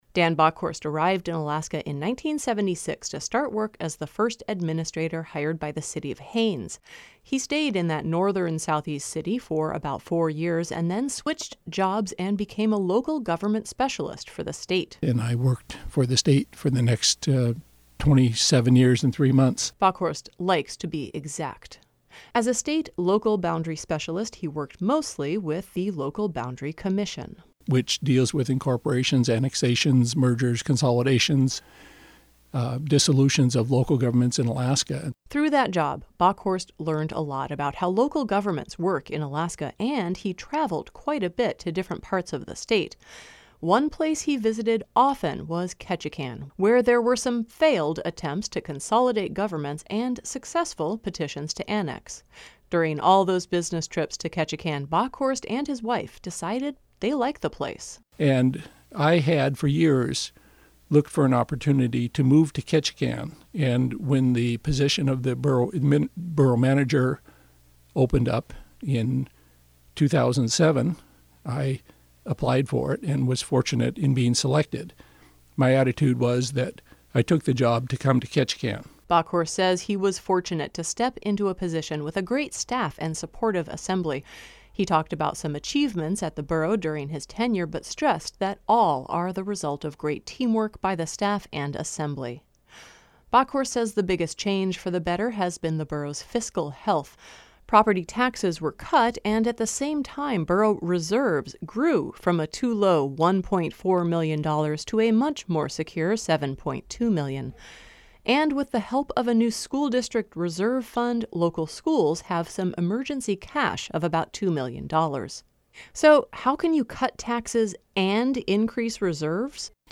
He came by KRBD to talk about his decades of public service, and his plan to relax a bit – but not too much.